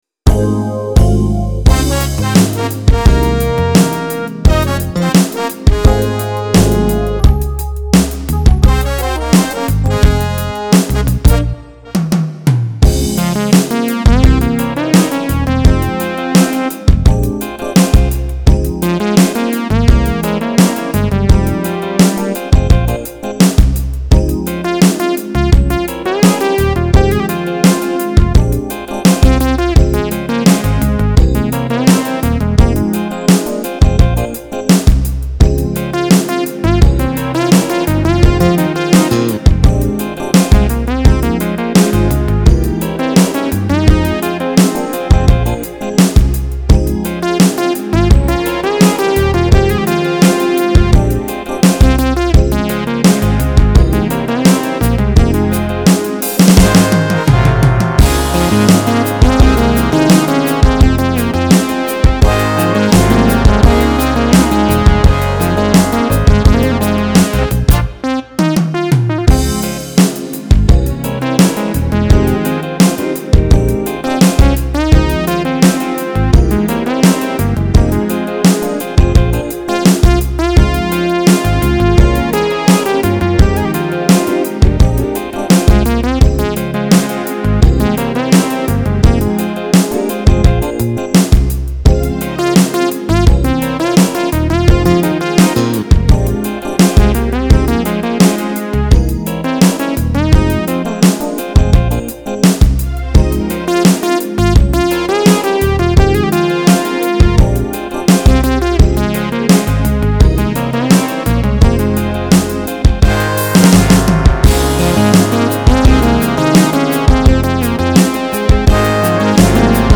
I mean, it’s definitely their style and those horns!